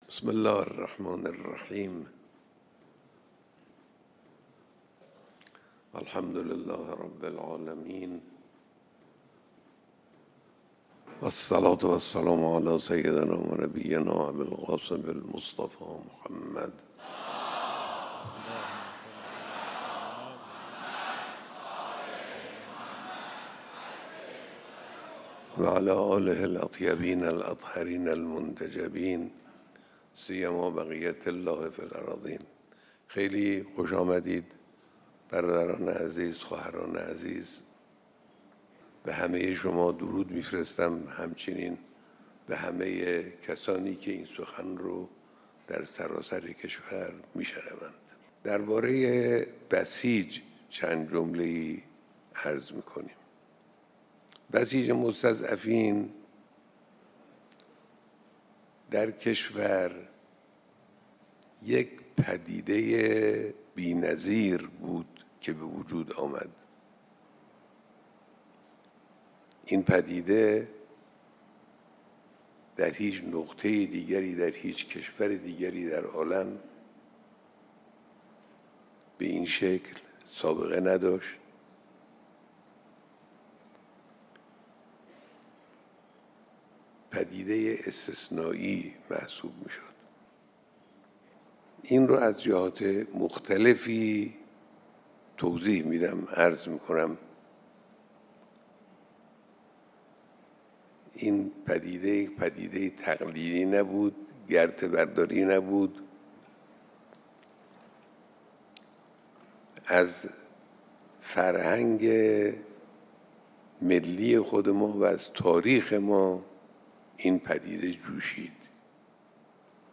متن، فیلم و صوت کامل دیدار صبح دیروز رهبر معظم انقلاب اسلامی با هزاران نفر از قشرهای مختلف بسیجی، به همراه عکس‌های منتشر شده از این دیدار در این بسته خبری منتشر می‌شود.
به گزارش گروه امام و رهبری خبرگزاری تسنیم، امام خامنه‌ای رهبر فرزانه انقلاب اسلامی صبح روز گذشته و همزمان با روز بسیج مستضعفان با هزاران نفر از قشرهای مختلف بسیجی دیدار و گفت‌وگو کردند.